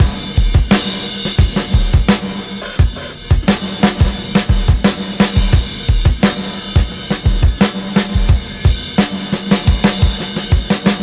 087bpm